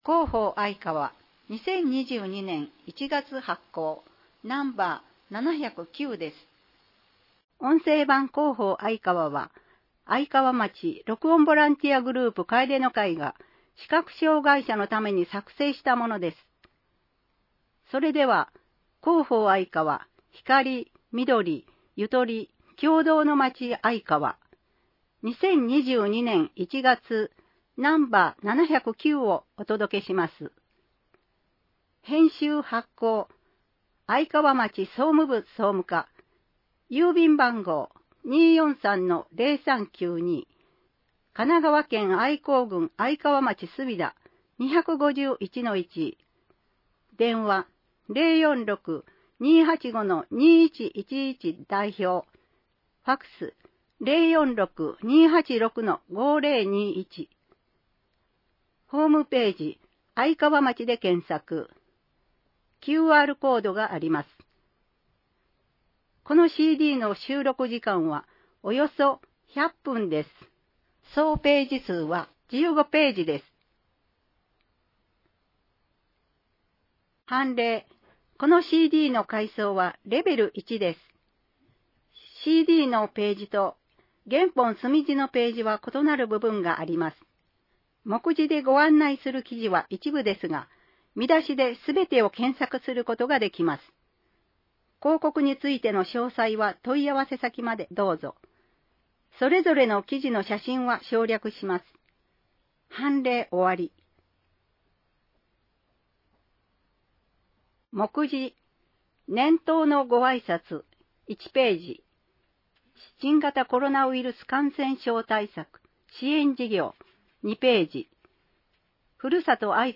(PDFファイル: 1011.1KB) 町政情報館 (PDFファイル: 2.5MB) トピックス (PDFファイル: 507.7KB) インフォメーション (PDFファイル: 4.5MB) あいかわカレンダー (PDFファイル: 2.3MB) 音声版「広報あいかわ」 音声版「広報あいかわ」は、「愛川町録音ボランティアグループ かえでの会」の皆さんが、視覚障がい者の方々のために「広報あいかわ」を録音したものです。